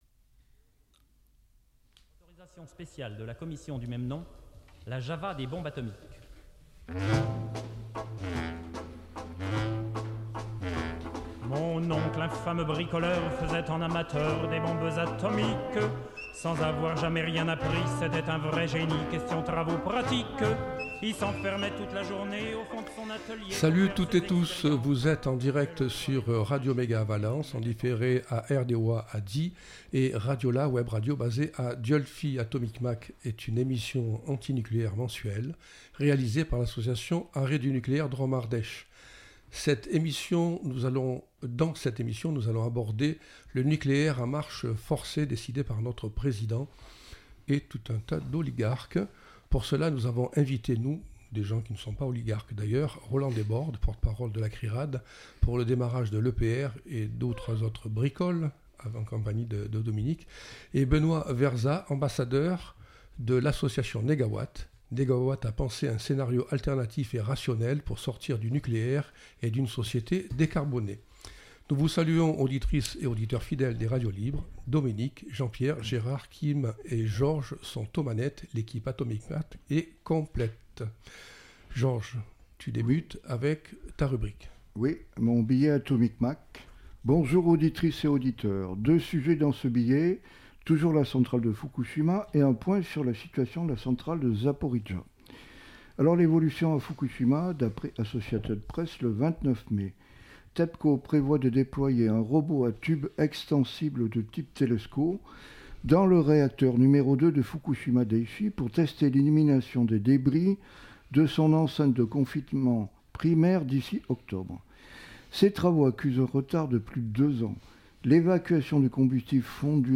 Atomic Mac, c’est l’émission radio mensuelle de l’association Arrêt du nucléaire Drôme-Ardèche. Du nucléaire civil au nucléaire militaire, de l’actualité locale à Cruas ou Tricastin et jusqu’à Bure, Fukushima ou ailleurs, tout y passe !